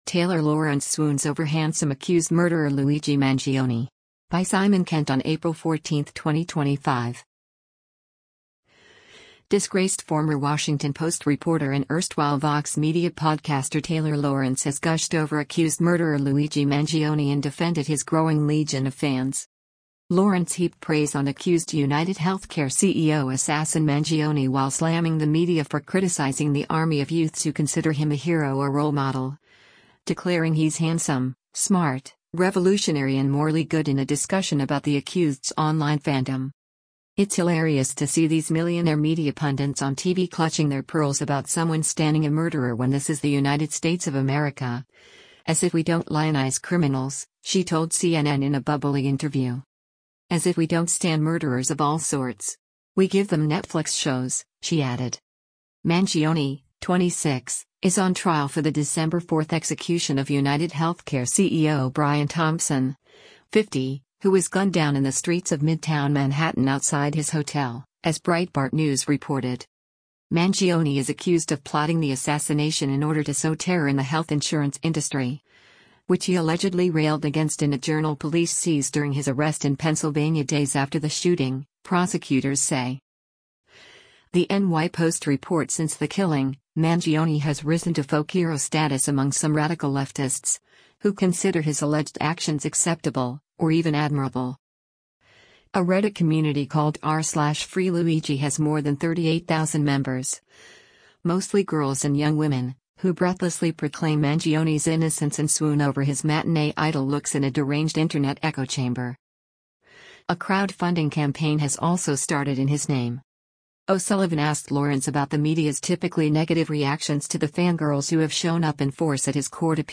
“It’s hilarious to see these millionaire media pundits on TV clutching their pearls about someone stanning a murderer when this is the United States of America — as if we don’t lionize criminals,” she told CNN in a bubbly interview.
“You’re gonna see women especially that feel like, ‘oh my God, here’s this man who’s a revolutionary, who’s famous, who’s handsome, who’s young, who’s smart, he’s a person that seems like this morally good man,’ which is hard to find,” Lorenz said as she and CNN correspondent Donnie O’Sullivan laughed along merrily.